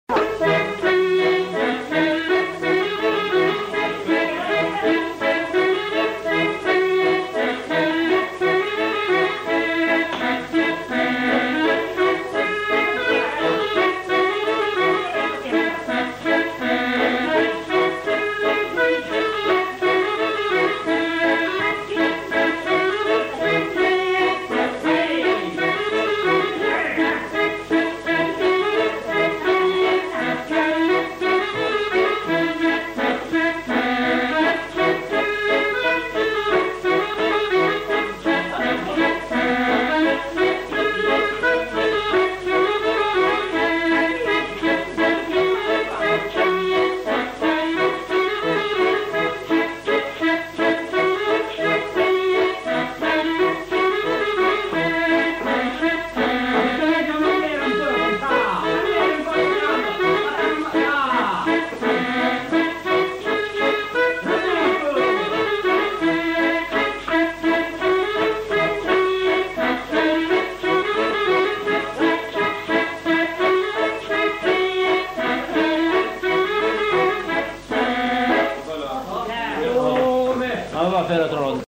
Répertoire de danses joué à l'accordéon diatonique
enquêtes sonores
Valse vienne (varsovienne)